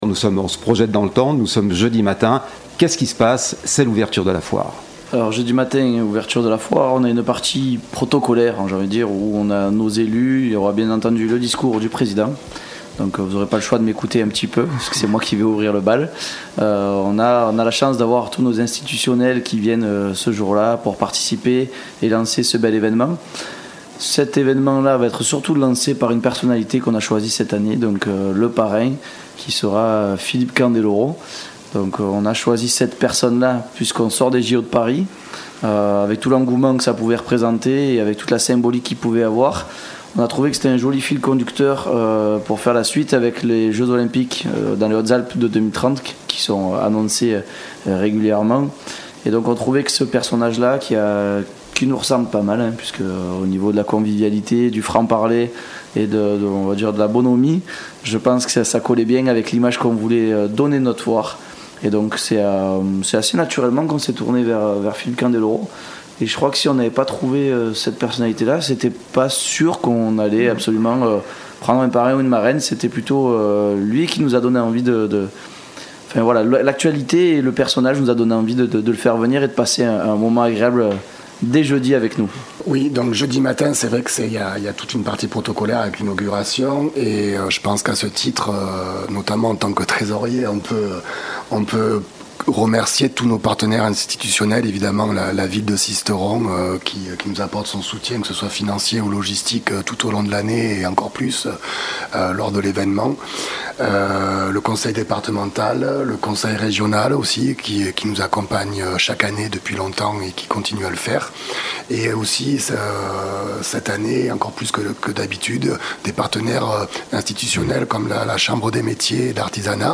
Retrouvez un extrait de l'émission diffusée sur Fréquence Mistral ( sur le site, Rubrique: Emissions Sisteron)